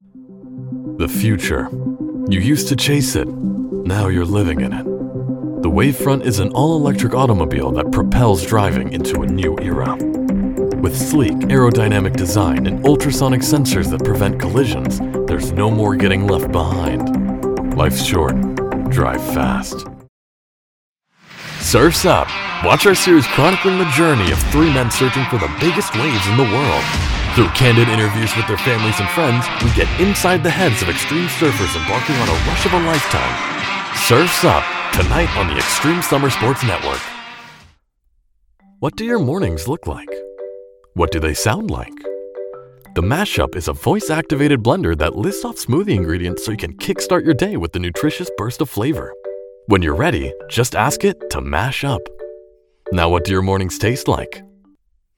Resonant, calming, powerful, and authentic.
English - USA and Canada
Young Adult
Middle Aged
Commercial